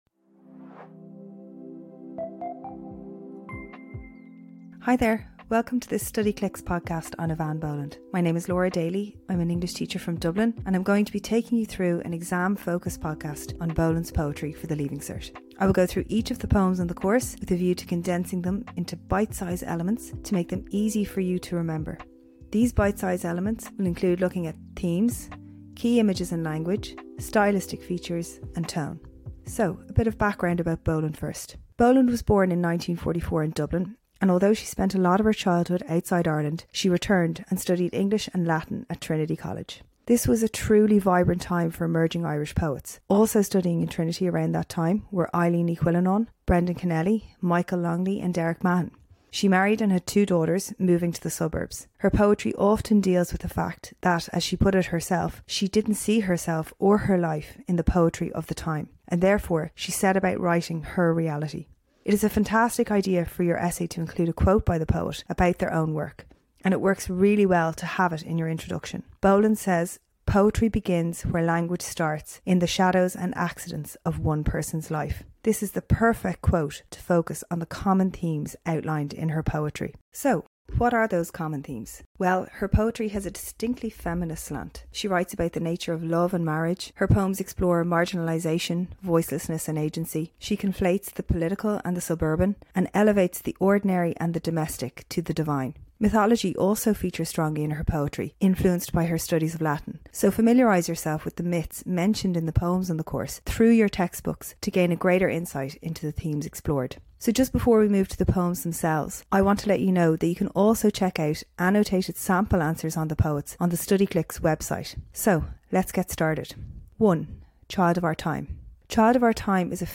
English teacher